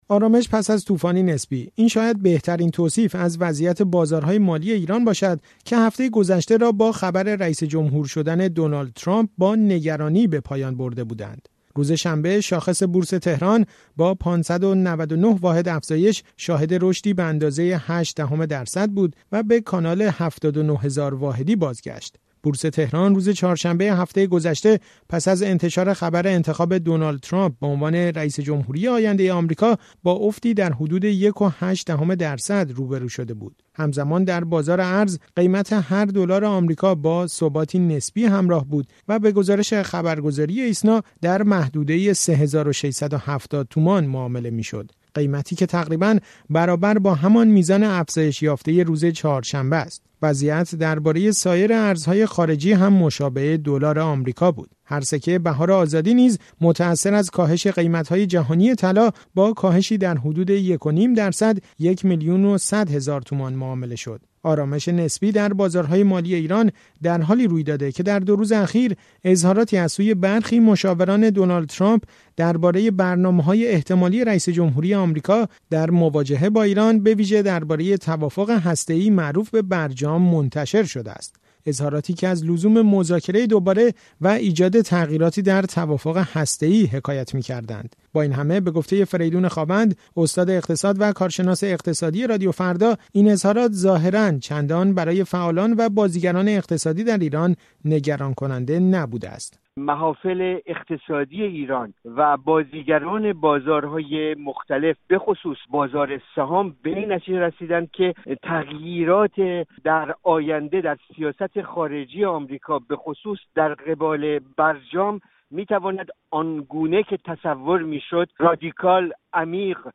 گزارشی از تحولات اخیر بازارهای مالی ایران: